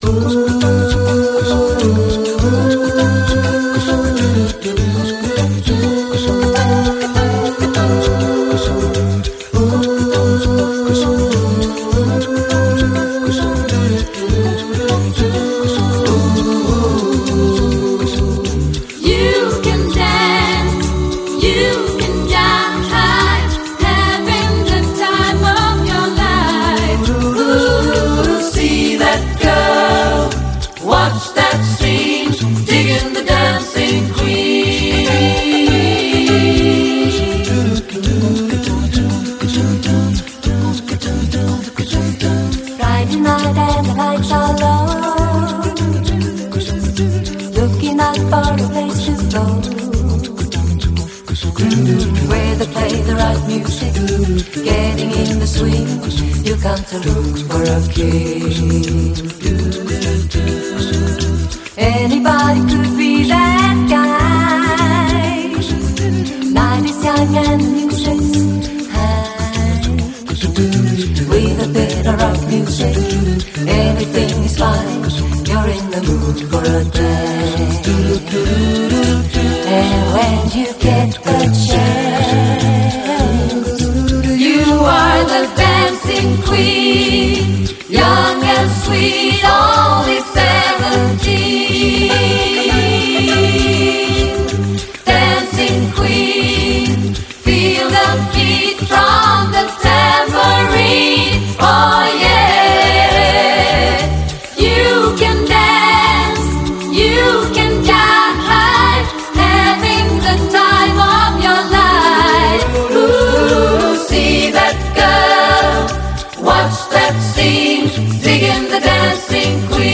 A Capella Versión